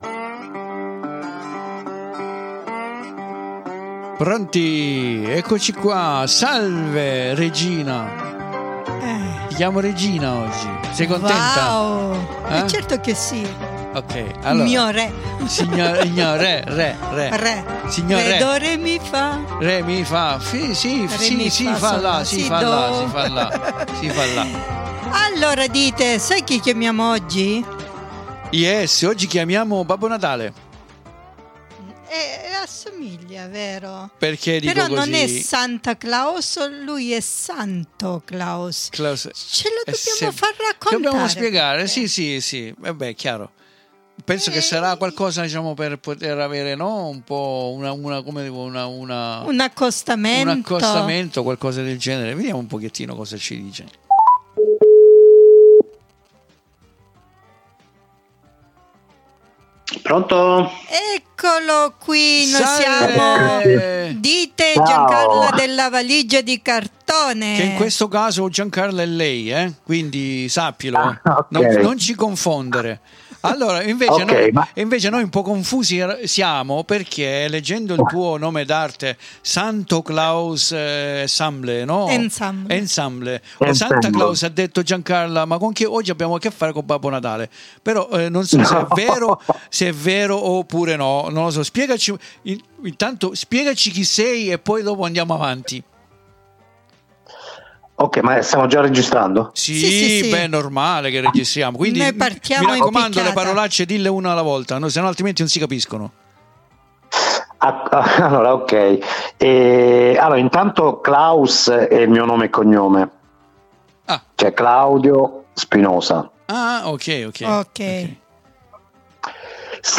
IO NON VOGLIO AGGIUNGERVI ALTRO , PERCHÉ É MOLTO BELLO ASCOLTARE DA LUI LA SUA STORIA, RACCHIUSA POI IN QUESTA INTERVISTA E CONDIVISA QUI IN DESCRIZIONE.